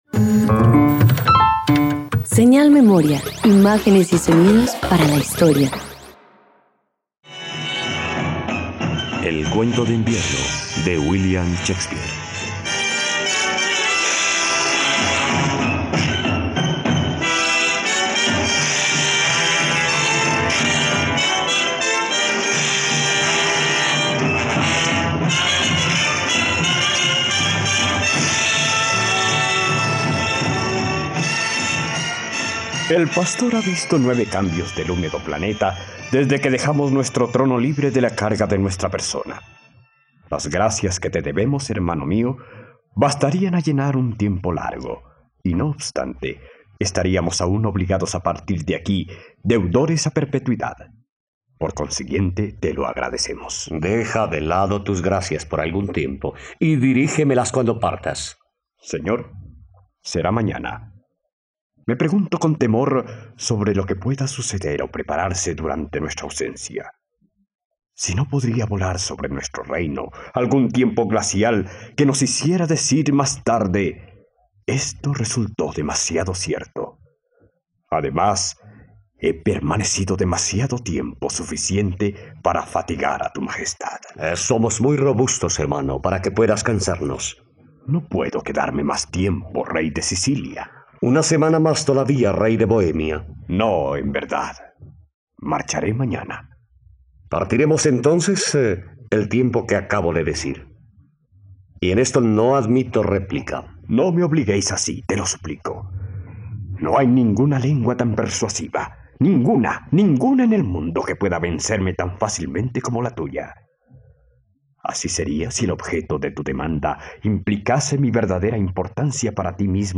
..Radioteatro. Escucha la adaptación de la obra "El cuento de invierno" del dramaturgo inglés William Shakespeare en la plataforma de streaming RTVCPlay.